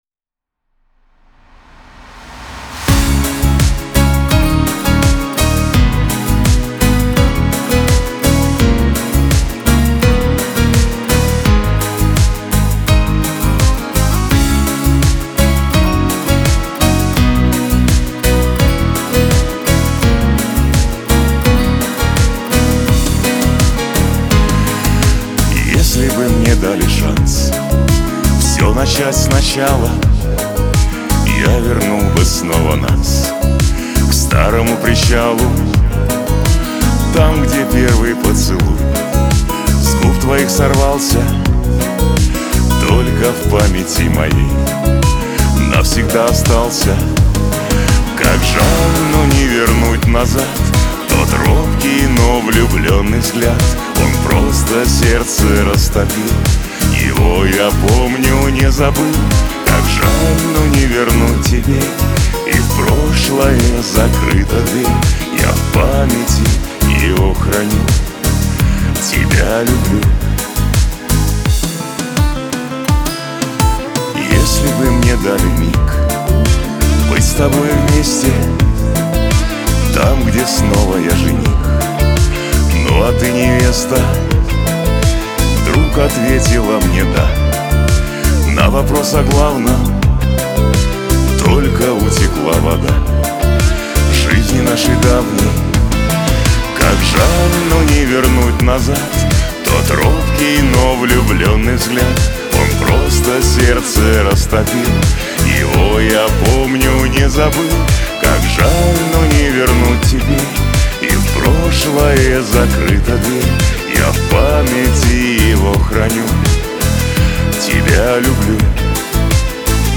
эстрада , Лирика , pop , диско